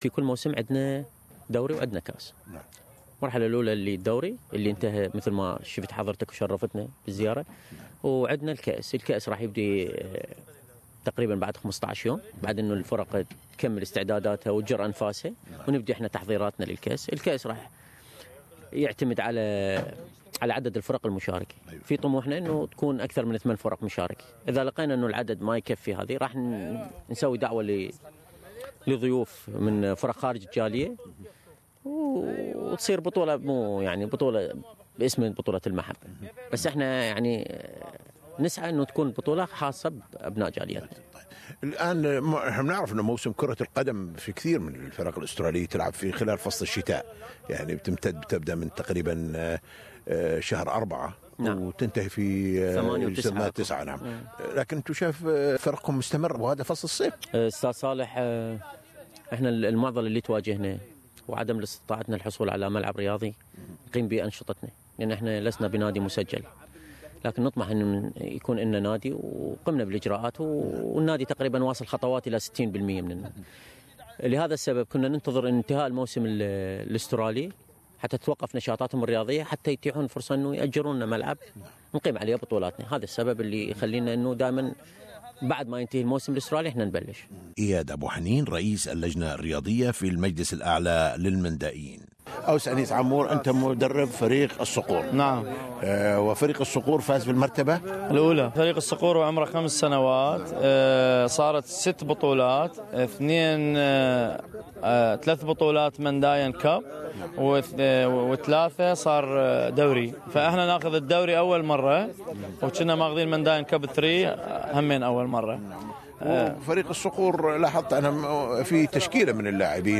SBS Arabic 24 coverage of Mendaian soccer Shampionship, held on Saturday 19 November 2016 . Interviews with participants in this event .